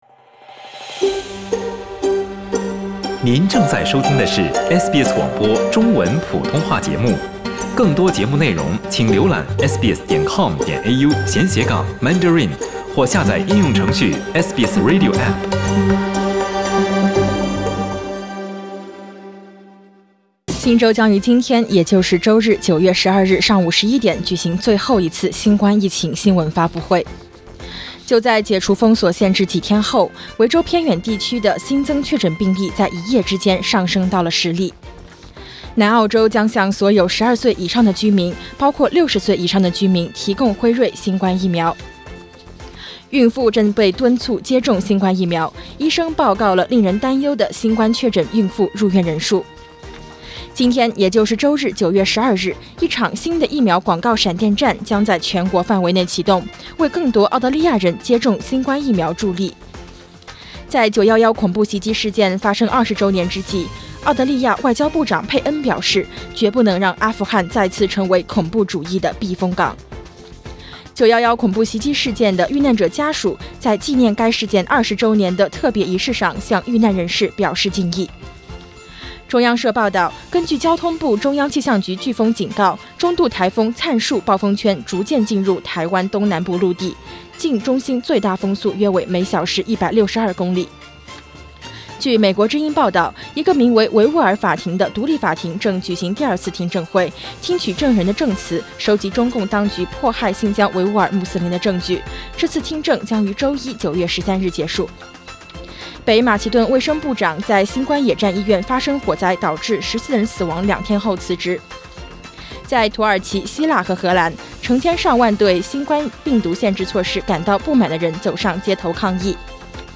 SBS早新闻（9月12日）